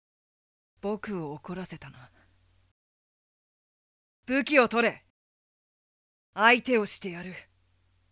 【サンプルセリフ】